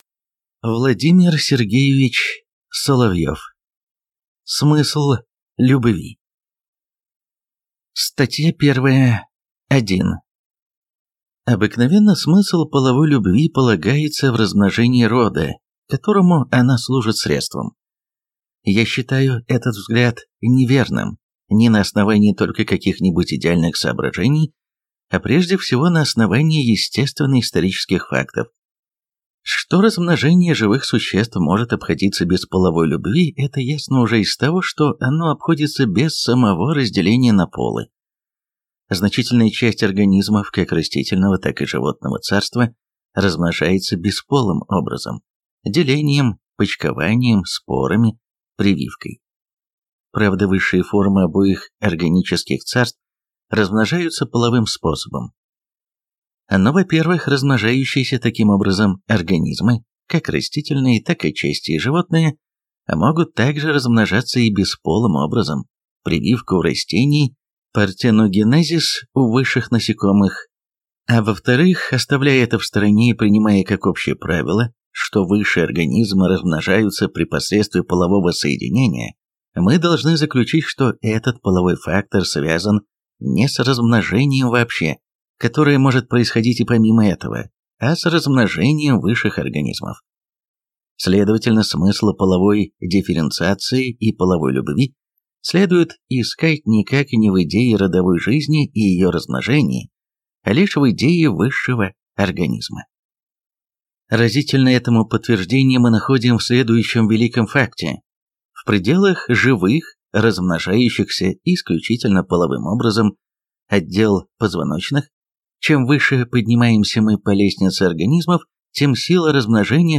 Аудиокнига Смысл любви | Библиотека аудиокниг